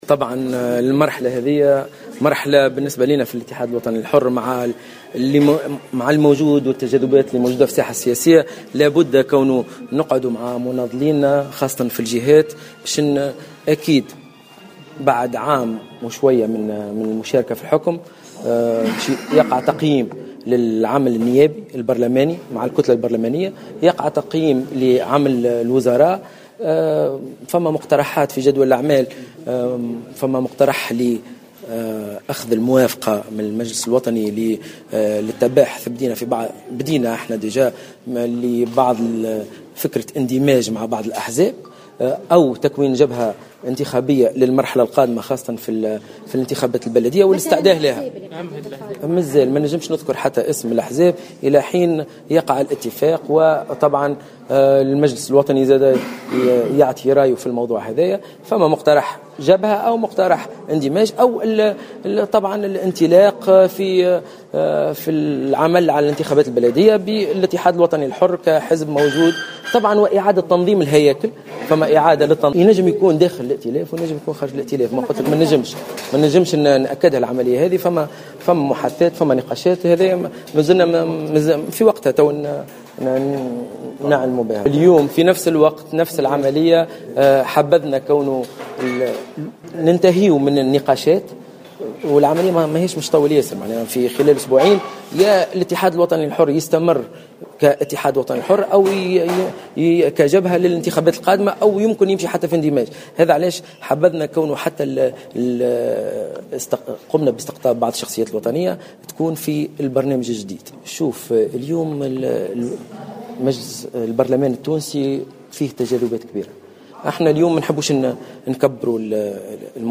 أكد رئيس حزب الاتحاد الوطني الحر، سلييم الرياحي في تصريح إعلامي اليوم الجمعة على هامش انعقاد المجلس الوطني الاستثنائي للحزب في الحمامات، أن هذه المناسبة تعد فرصة لتقييم أداء الكتلة البرلمانية والوزراء بعد نحو سنة من المشاركة في الحكم.